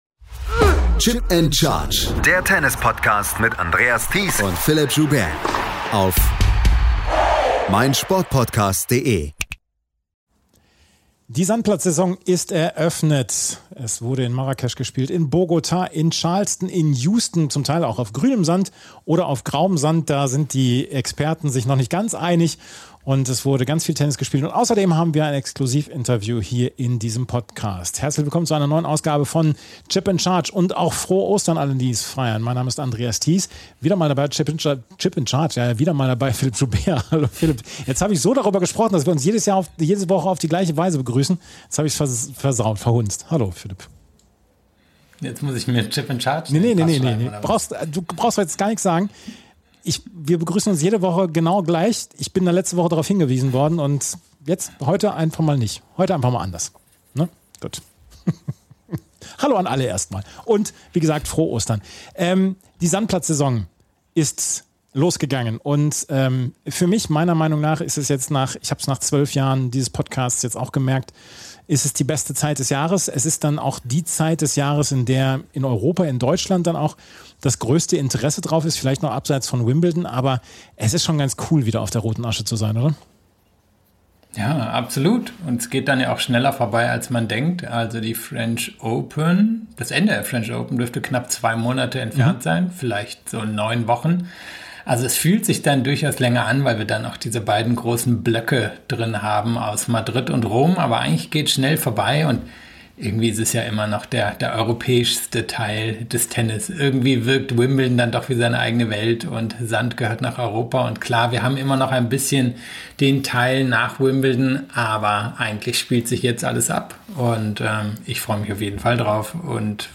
Exklusiv-Interview